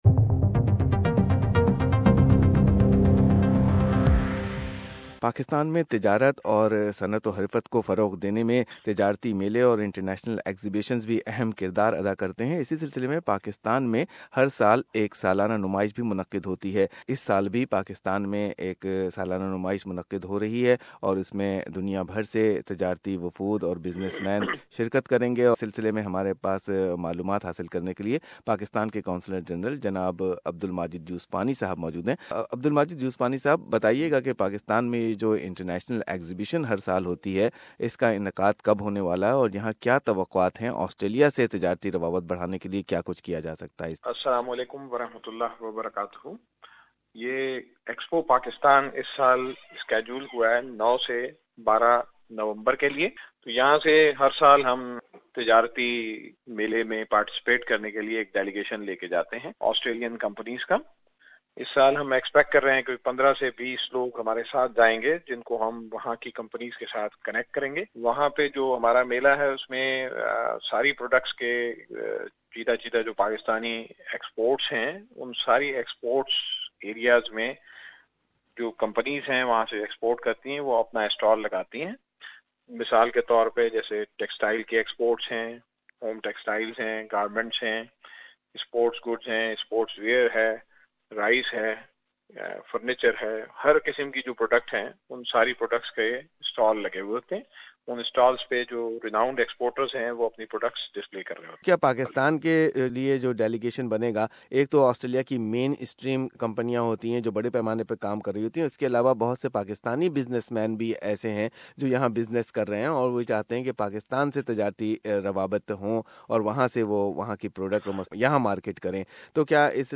Consul General of Pakistan in Sydney Hon Abdul Majid Yousfani is sharing the details how to become a part of 10th Expo Pakistan Nov 9th - 12th 2017.